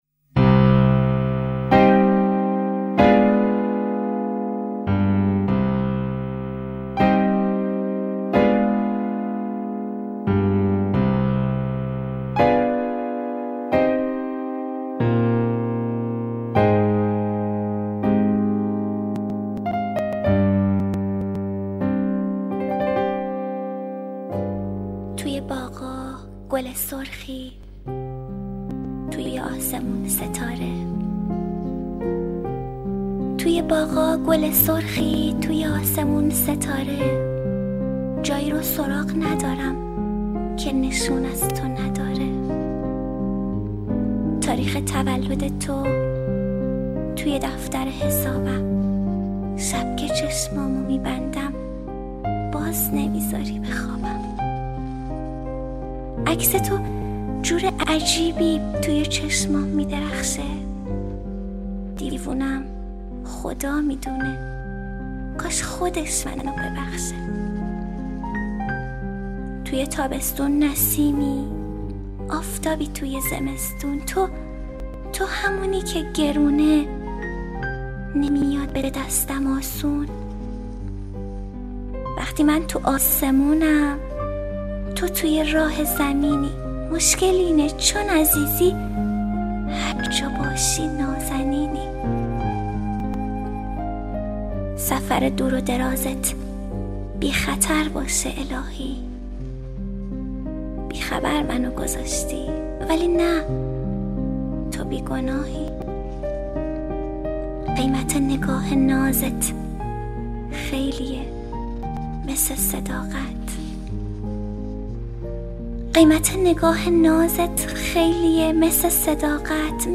دانلود دکلمه مثل فصل ها با صدای مریم حیدرزاده با متن دکلمه
گوینده :   [مریم حیدرزاده]